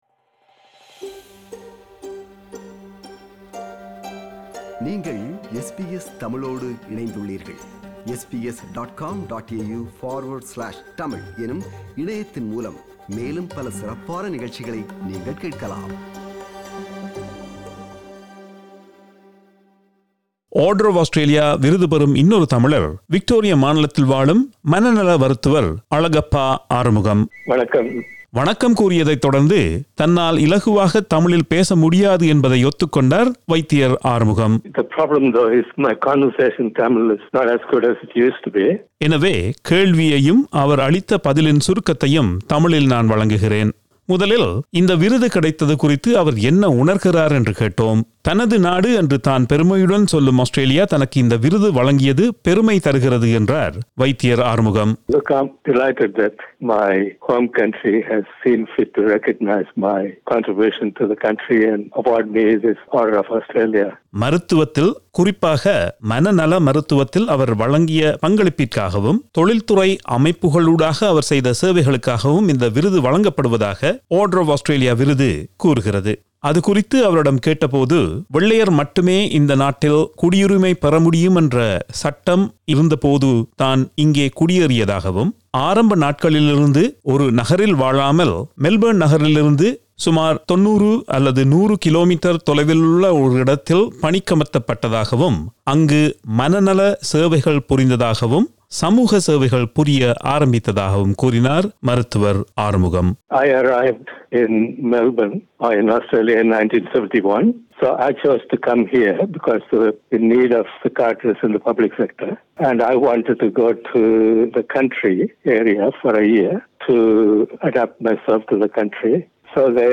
அவரை தொலைபேசி வழியாக நேர்காண்கிறார்